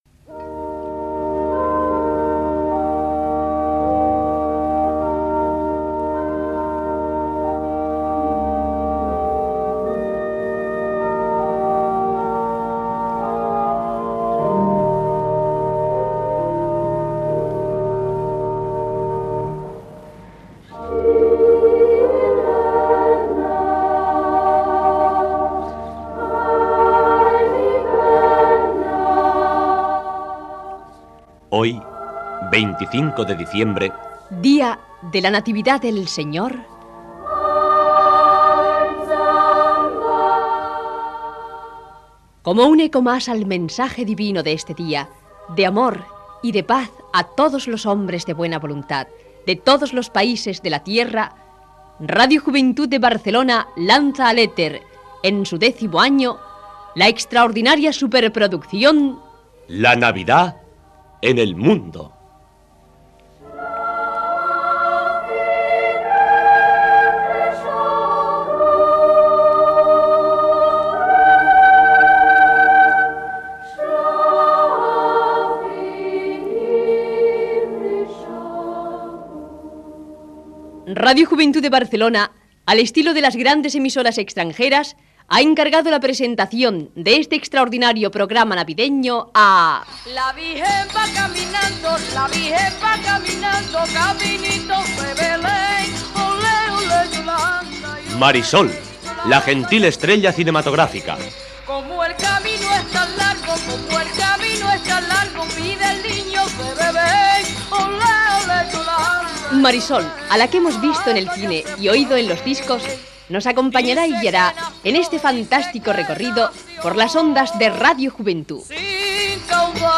Careta d'entrada de la desena edició del programa amb la presentació i una nadala.
Aquest programa va ser presentat per Marisol (Pepa Flores).